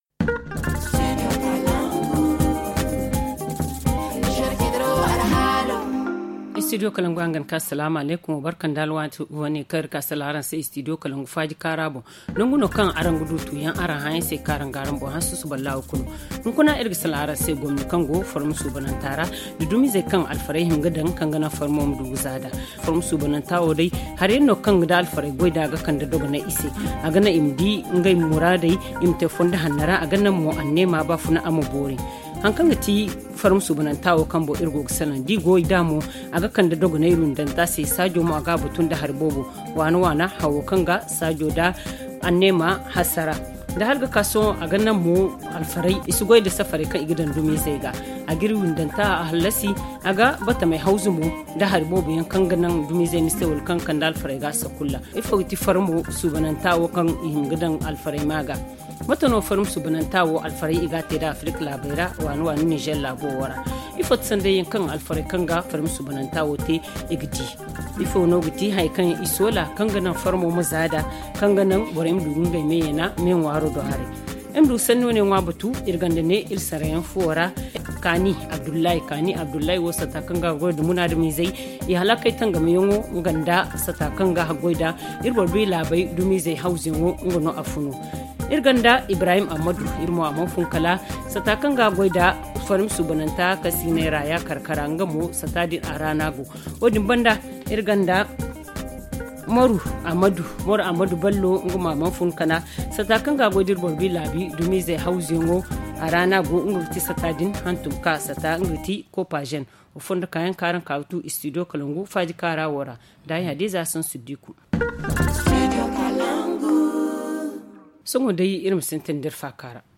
ZA Le forum en Zarma https